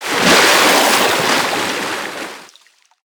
Sfx_creature_snowstalkerbaby_breach_01.ogg